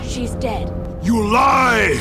One of the most iconic Arnold Schwarzenegger quotes.